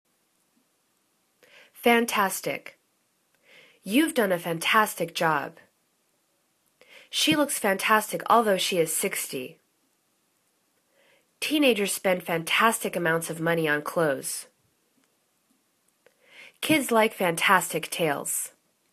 fan.tas.tic     /fan'tastik/    adj